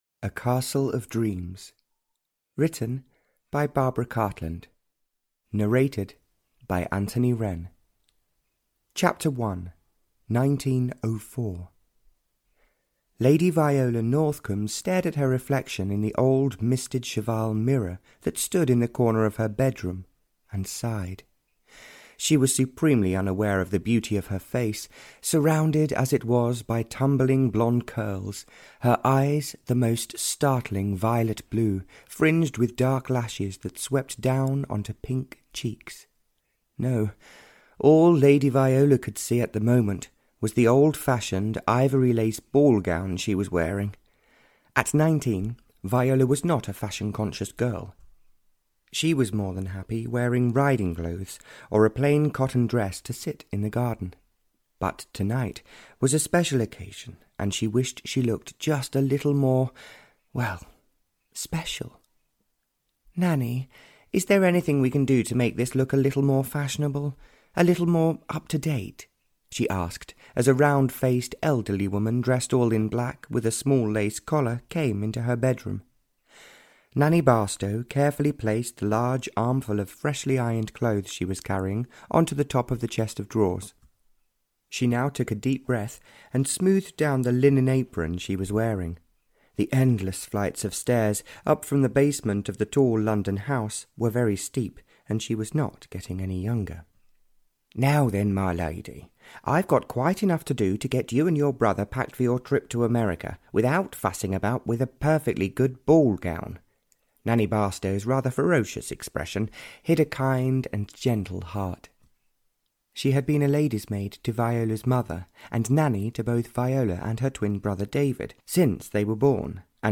A Castle Of Dreams (Barbara Cartland's Pink Collection 59) (EN) audiokniha
Ukázka z knihy